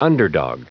Prononciation du mot underdog en anglais (fichier audio)
Prononciation du mot : underdog